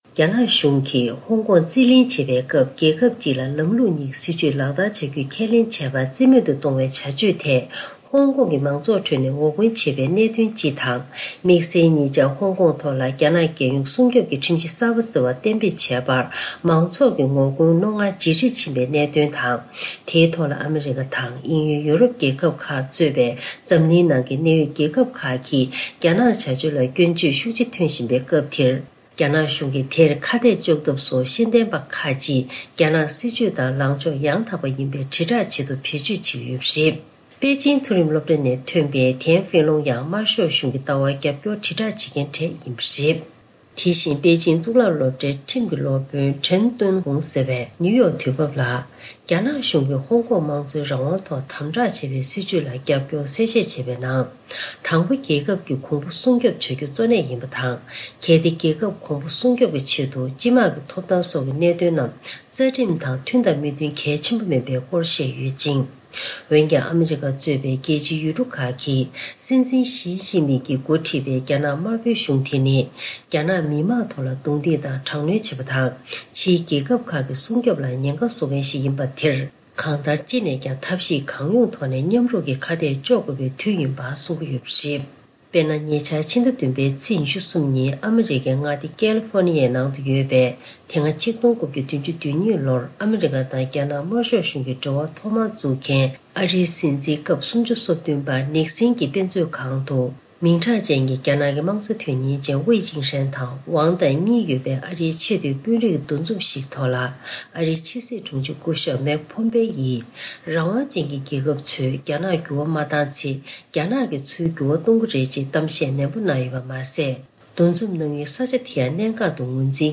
སྒྲ་ལྡན་གསར་འགྱུར། སྒྲ་ཕབ་ལེན།
ཐེངས་འདིའི་གསར་འགྱུར་དཔྱད་གཏམ་གྱི་ལེ་ཚན་ནང་།